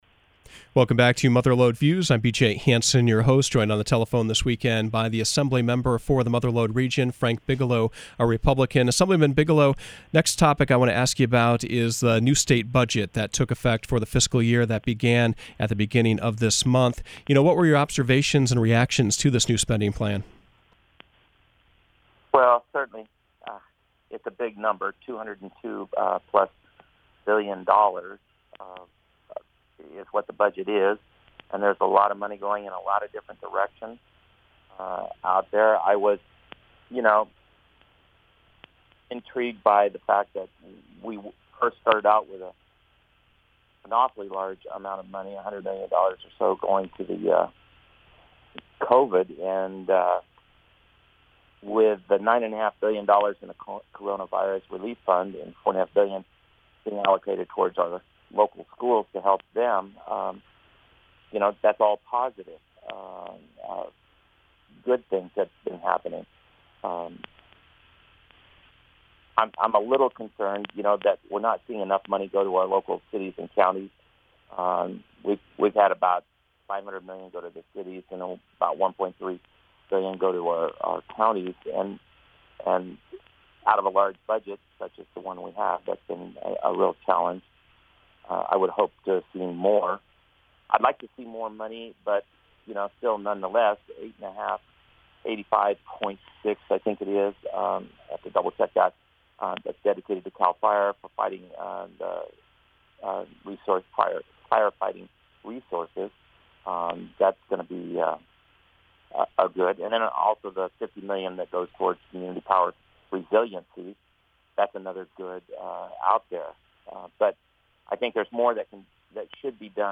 Mother Lode Views featured District Five California Assemblyman Frank Bigelow.